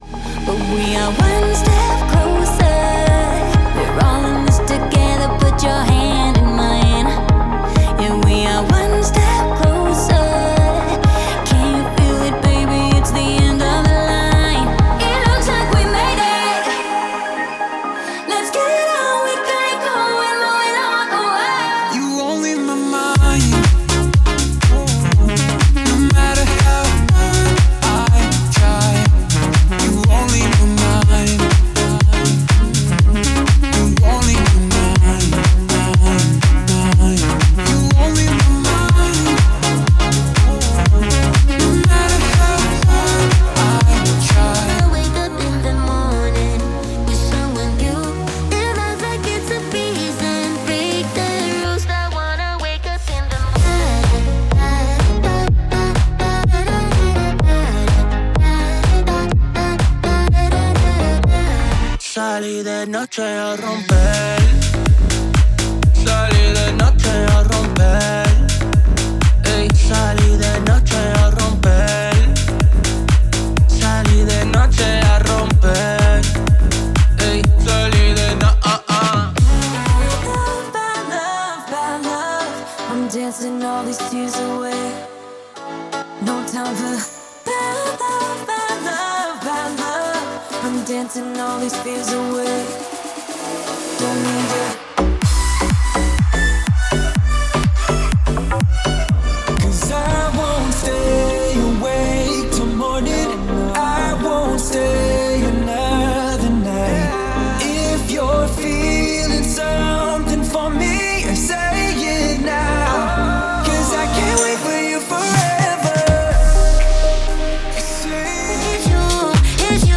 Lively, upbeat,
high-tempo pop
and dance music
with vibrant rhythms.